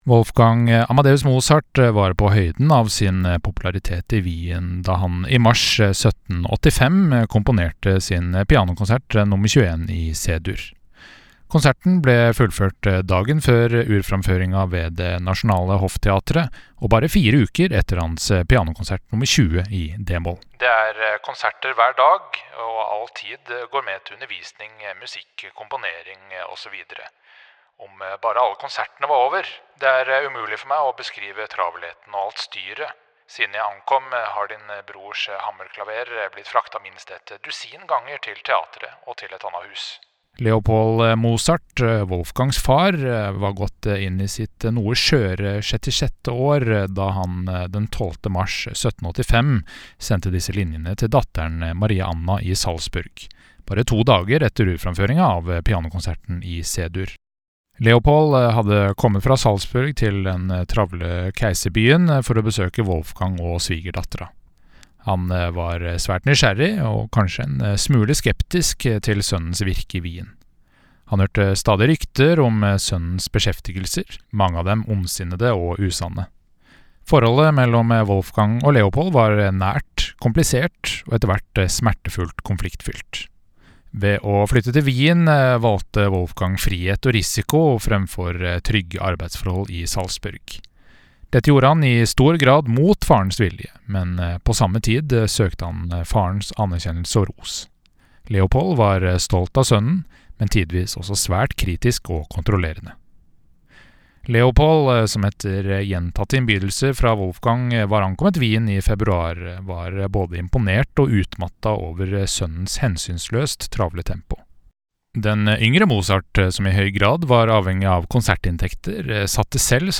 VERKOMTALE-Wolfgang-Amadeus-Mozarts-Pianokonsert-nr.-21.mp3